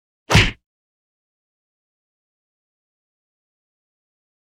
赤手空拳击中肉体5-YS070524.wav
通用动作/01人物/03武术动作类/空拳打斗/赤手空拳击中肉体5-YS070524.wav